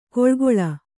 ♪ koḷgoḷa